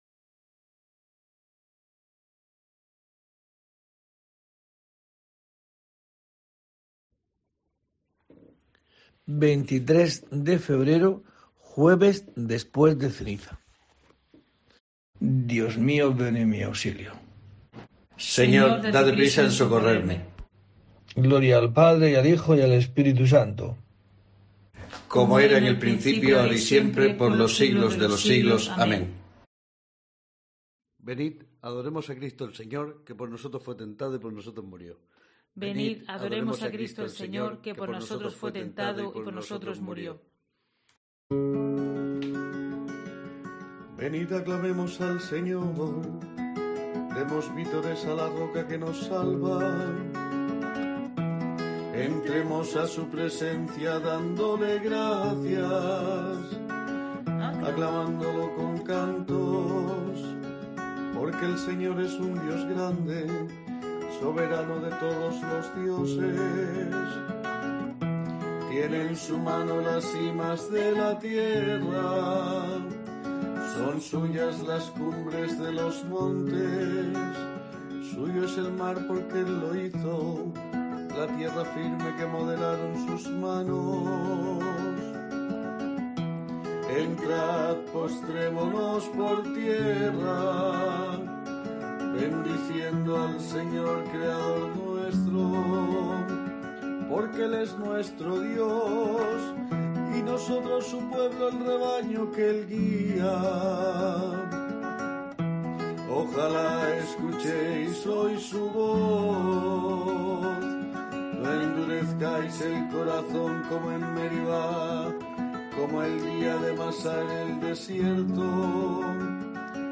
23 de febrero: COPE te trae el rezo diario de los Laudes para acompañarte